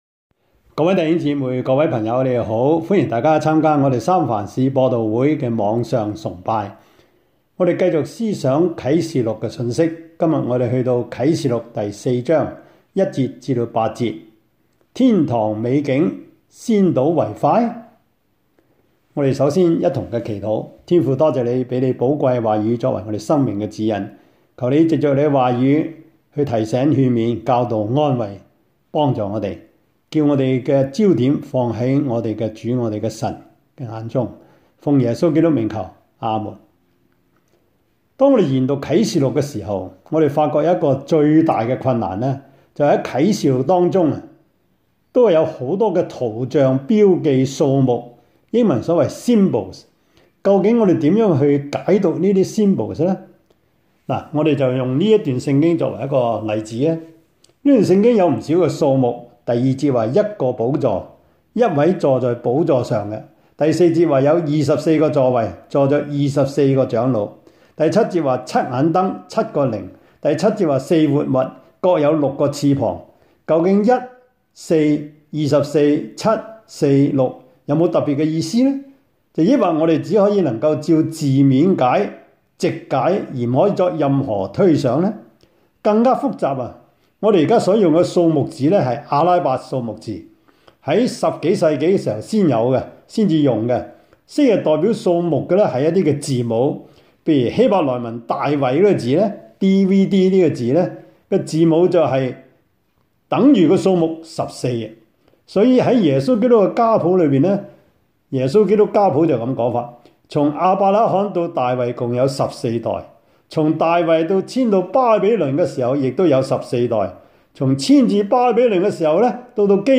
啟示錄 4:1-8 Service Type: 主日崇拜 Bible Text
Topics: 主日證道 « 處理罪惡的行動 天堂剪影 »